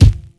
Boom-Bap Kick 80.wav